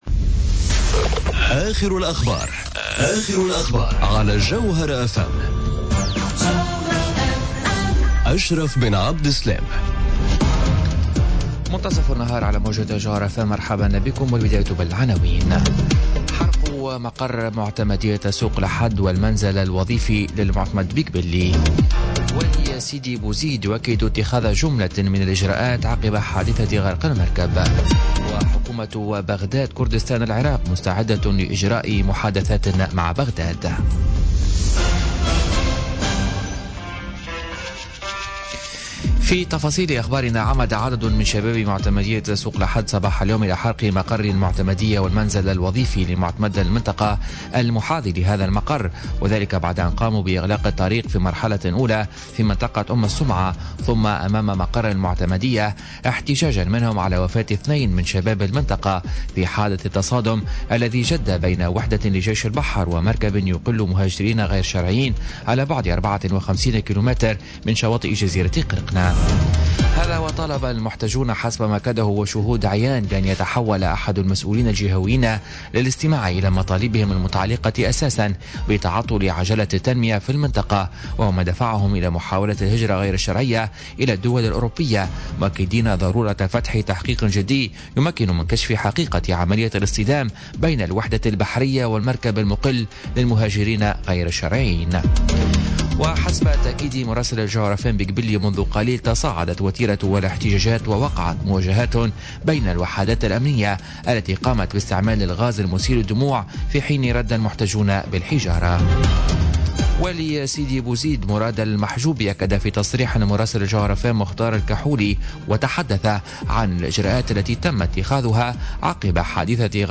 نشرة أخبار منتصف النهار ليوم الخميس 12 أكتوبر 2017